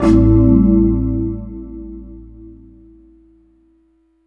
59 CHORD 1-R.wav